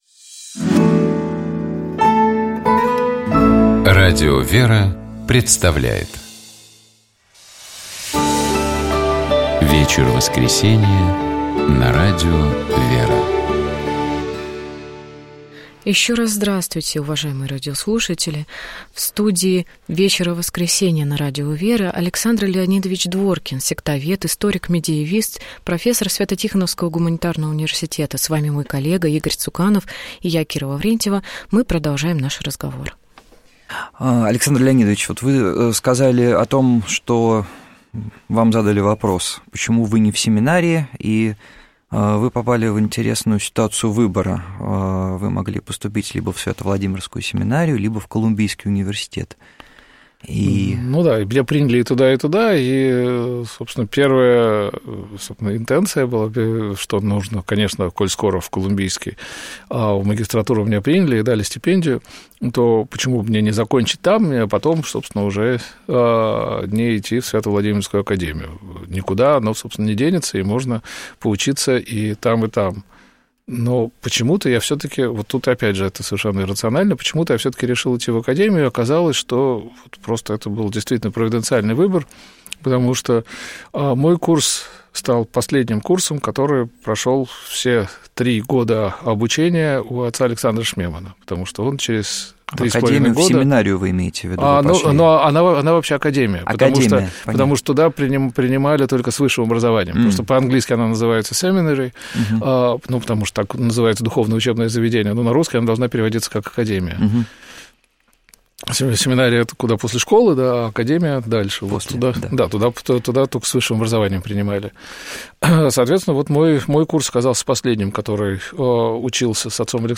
У нас в гостях был доктор философии и богословия, специалист по истории Средних веков, профессор Православного Свято-Тихоновского